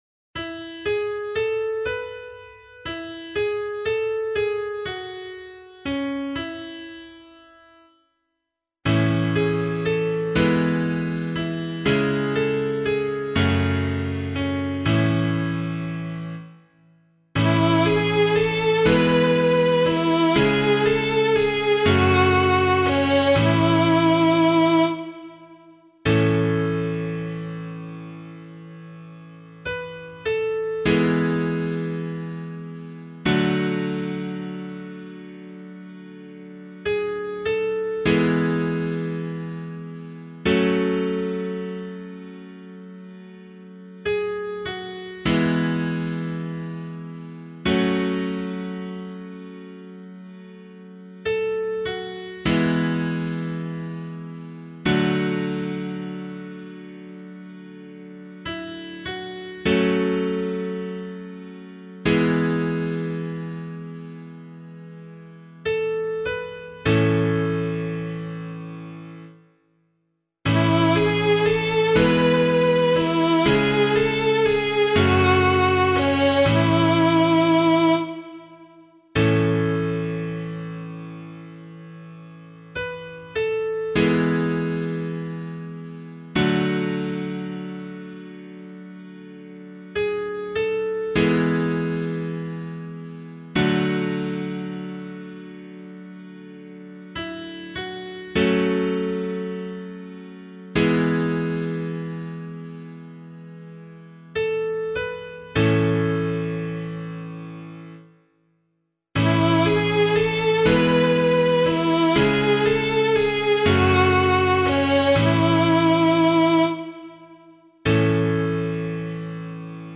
007 Holy Family Psalm C [LiturgyShare 8 - Oz] - piano.mp3